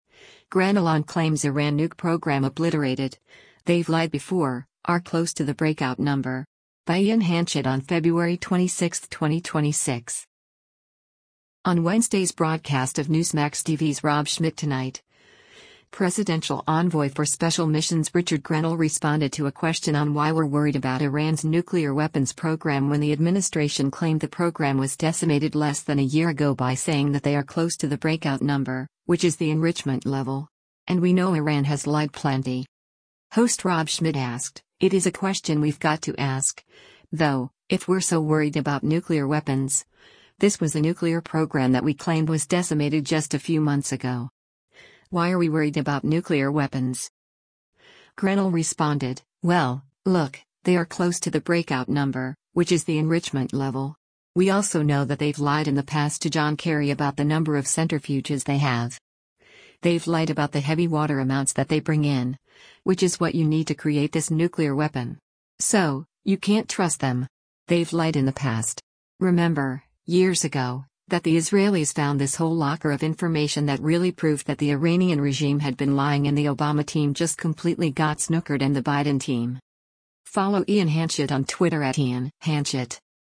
On Wednesday’s broadcast of Newsmax TV’s “Rob Schmitt Tonight,” Presidential Envoy for Special Missions Richard Grenell responded to a question on why we’re worried about Iran’s nuclear weapons program when the administration claimed the program was decimated less than a year ago by saying that “they are close to the breakout number, which is the enrichment level.”